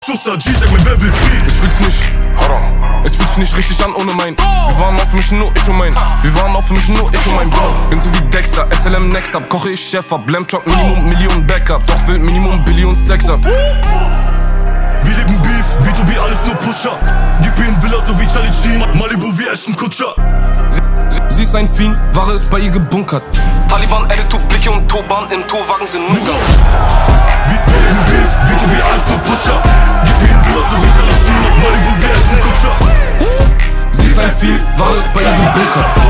Kategorien Rap